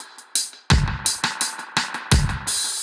Index of /musicradar/dub-designer-samples/85bpm/Beats
DD_BeatA_85-01.wav